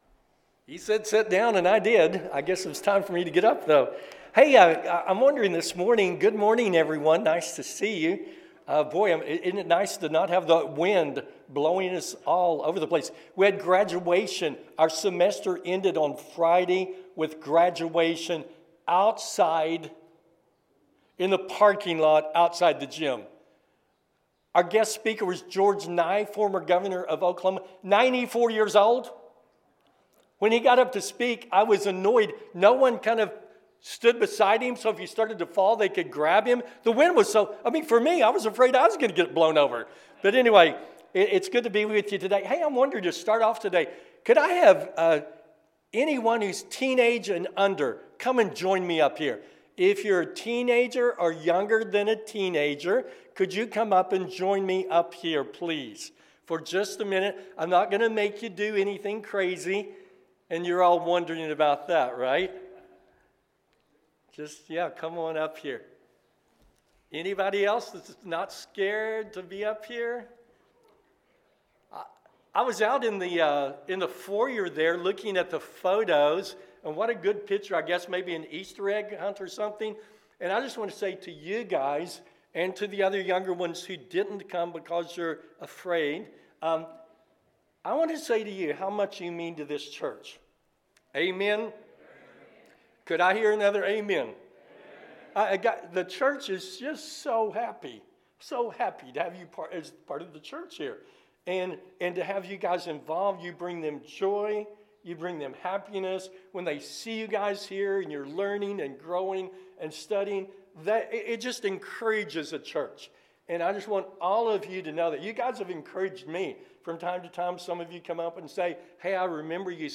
So You Want To Be Great? – Sermon